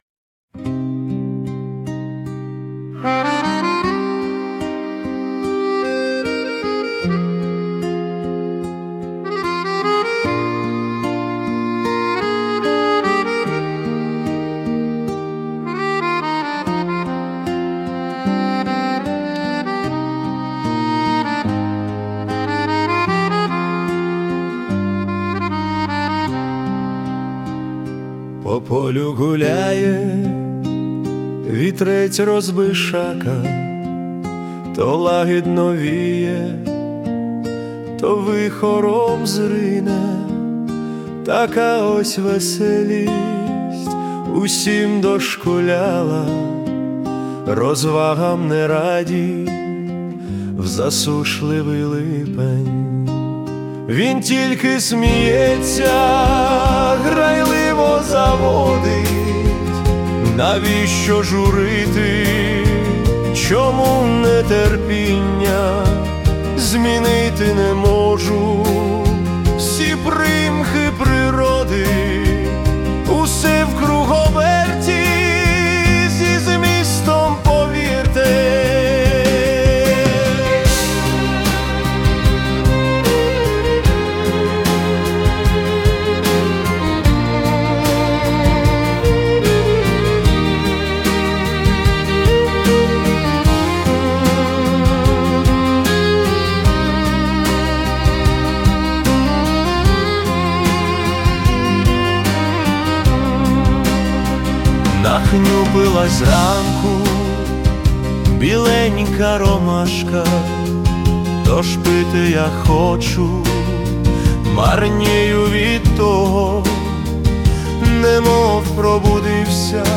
SUNO AI